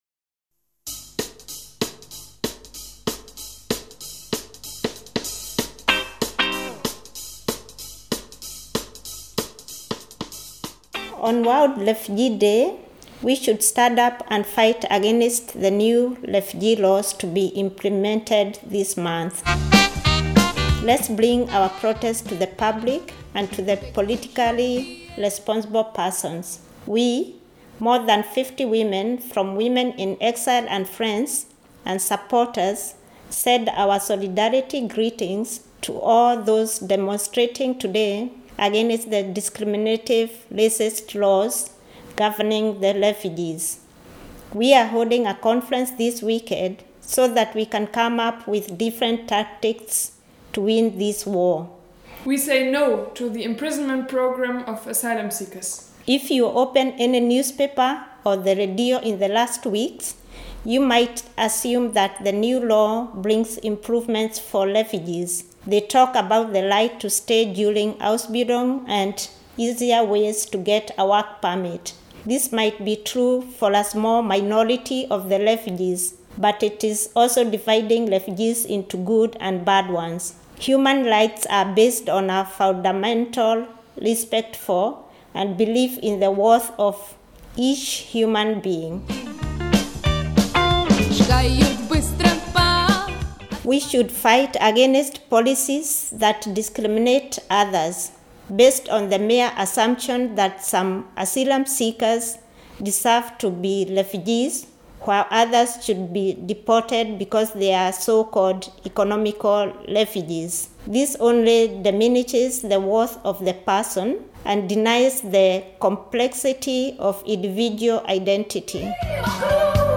von der Aktionskonferenz sendeten wir eine Grußbotschaft zur Demo, hier zum